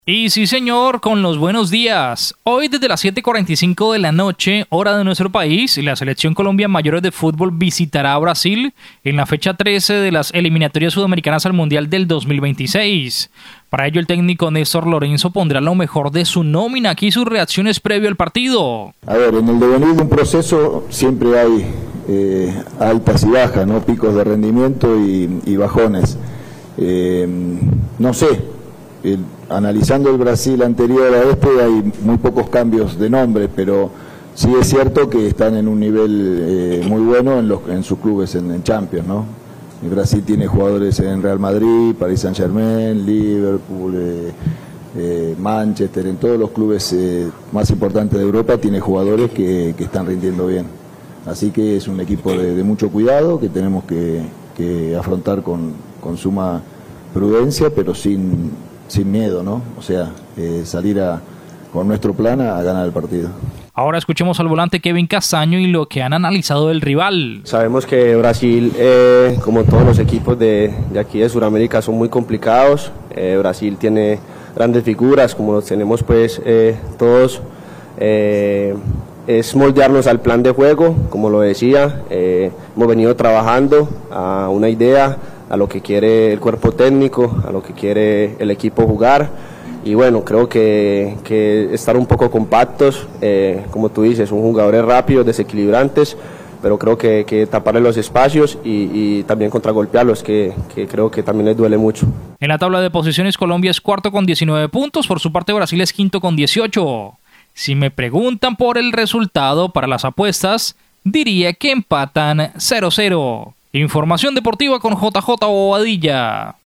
Para ello el técnico Néstor Lorenzo pondrá lo mejor de su nómina, aquí sus reacciones previas al partido.
VOZ_TITULAR_DEPORTES_20_MARZO.MP3